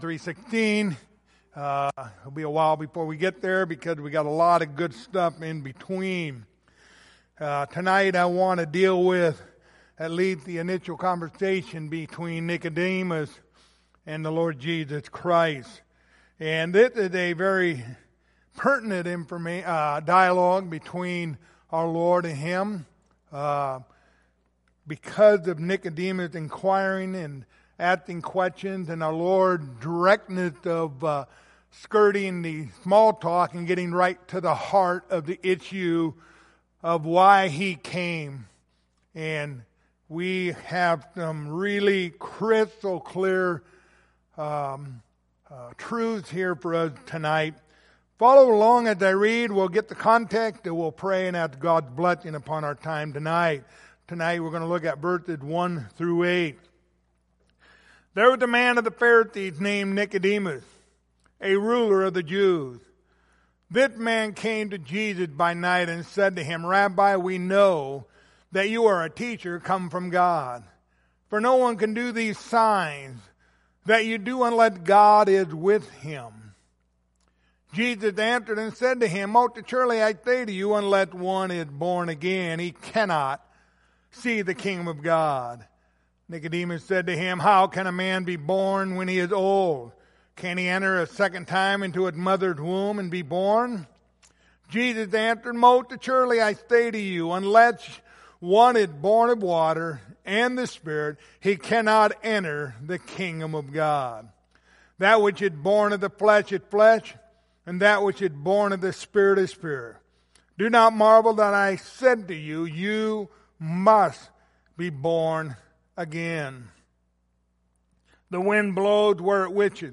Passage: John 3:1-8 Service Type: Wednesday Evening Topics